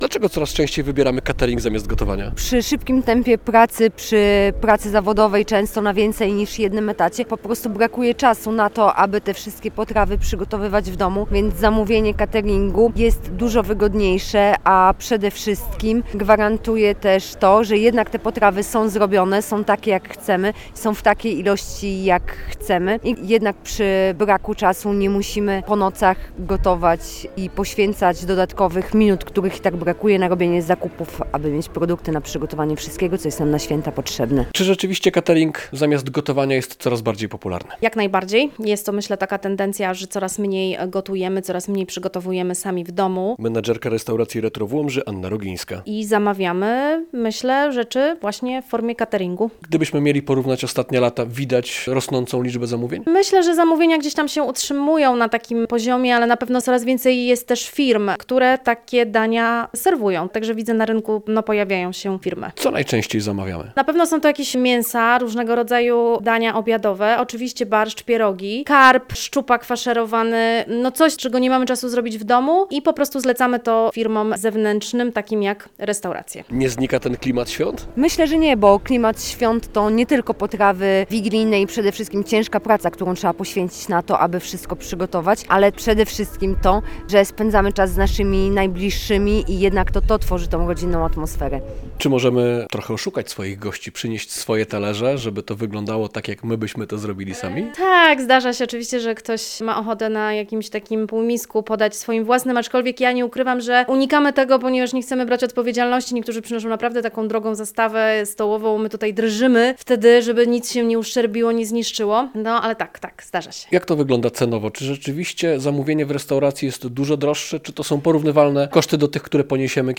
Świąteczny catering coraz bardziej popularny - relacja
Mieszkanki Łomży, z którymi rozmawiał nasz reporter, podkreślały, że catering jest dużym ułatwieniem, zwłaszcza kiedy się dużo pracuje, np. na więcej niż jednym etacie.